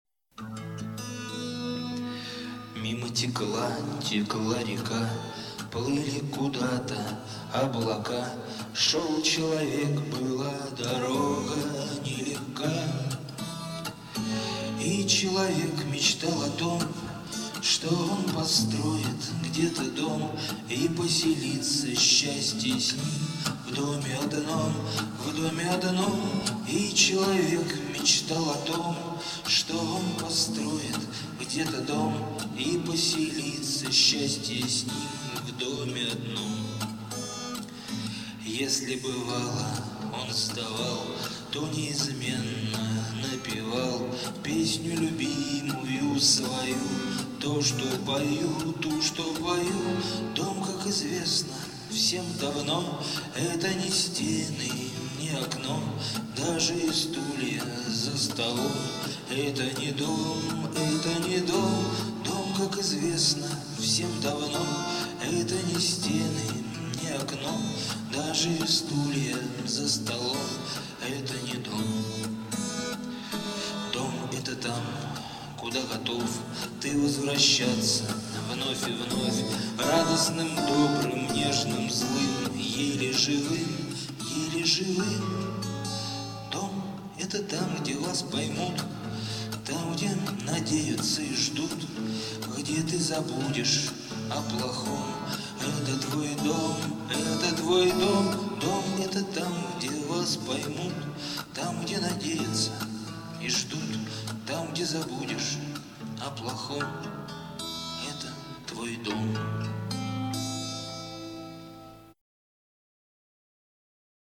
В авторском исполнении она звучит так: